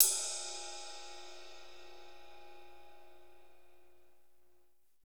Index of /90_sSampleCDs/Northstar - Drumscapes Roland/CYM_Cymbals 2/CYM_F_T Cyms x